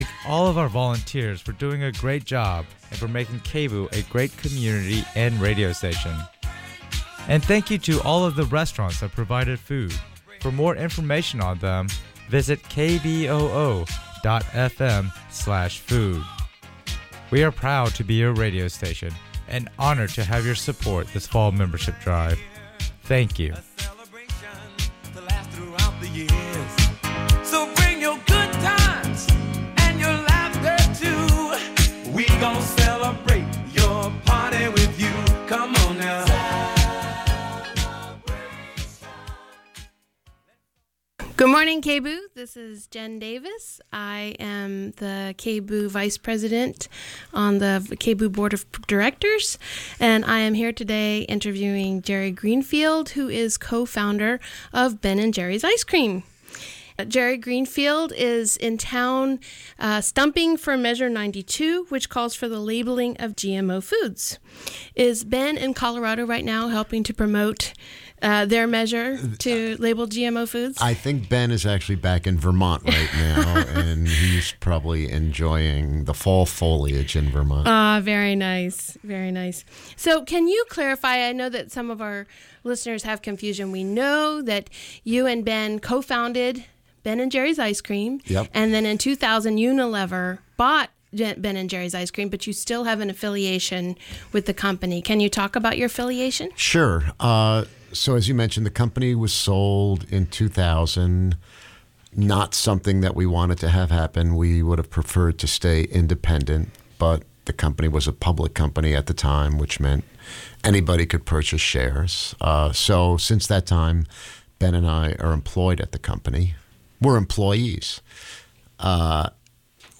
Grassroots women activists
live in the studio